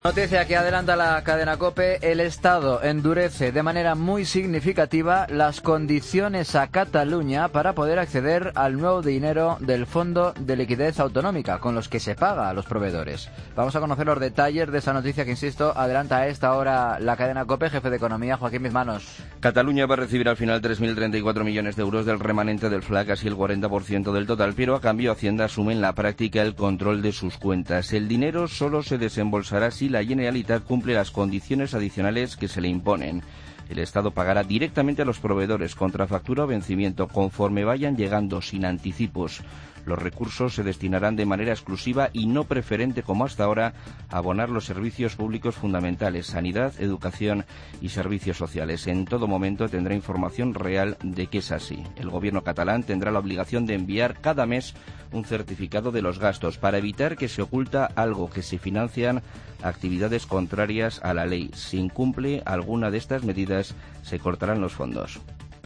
Duras condiciones a Cataluña para acceder al FLA. Crónica